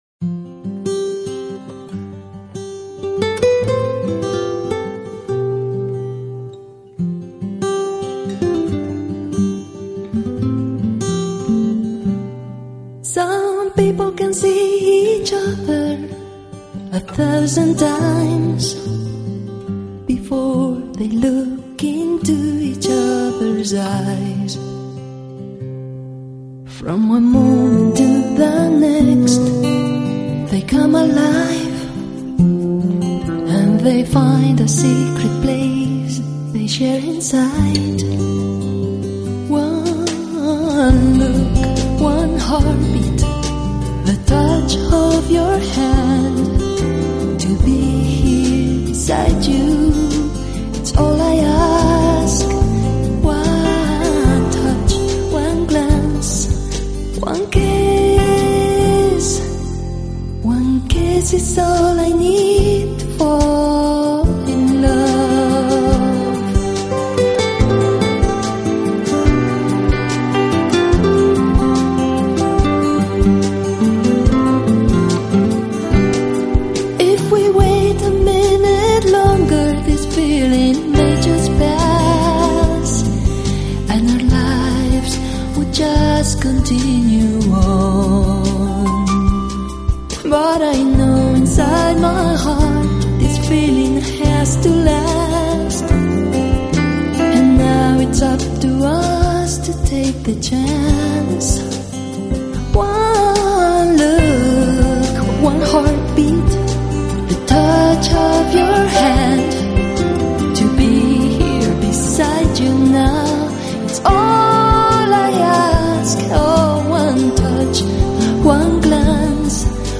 音乐风格：Jazz